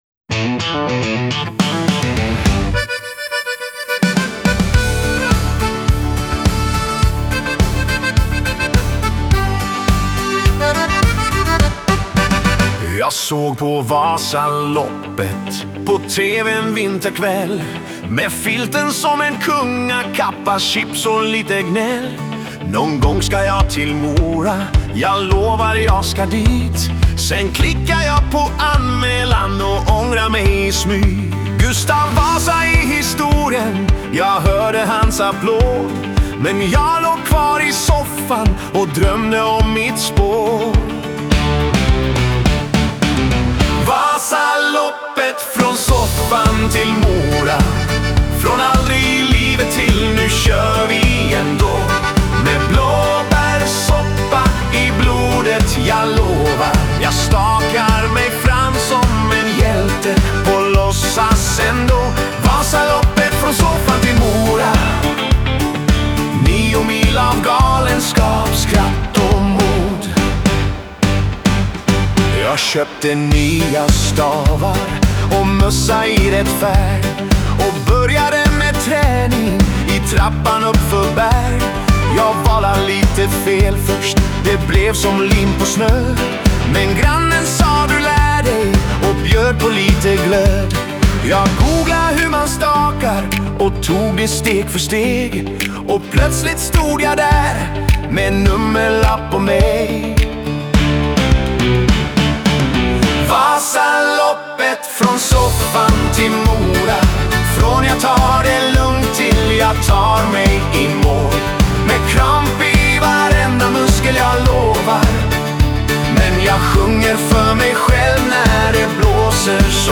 ett svenskt dansband från trakterna norr om Dalälven